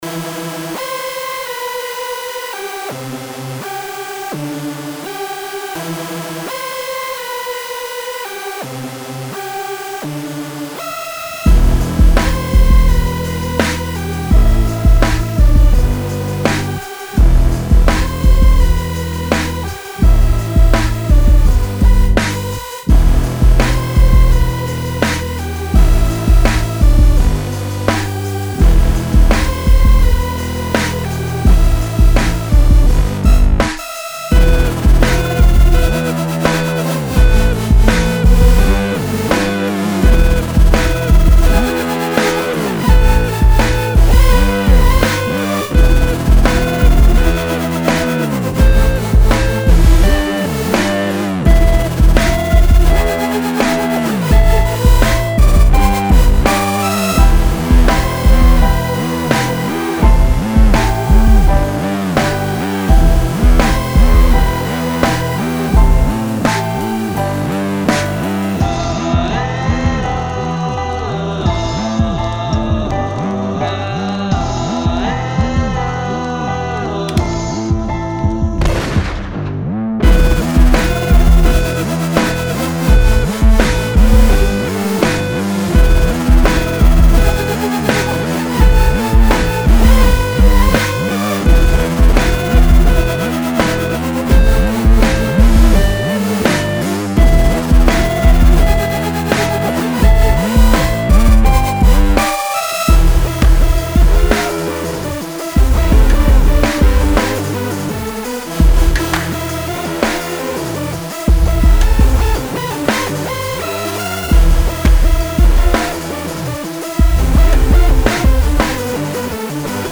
Electronic House, просьба оценить сведение, композицию
Сам трек родился из соло синта и небольшой мелодии, наверно это заметно.